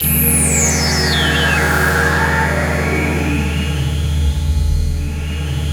17PAD 01  -L.wav